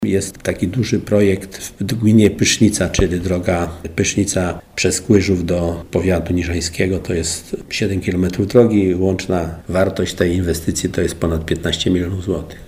Starosta Janusz Zarzeczny przyznaje , że jest to kosztowna inwestycja i bez środków zewnętrznych powiat nie będzie w stanie udźwignąć tego przedsięwzięcia: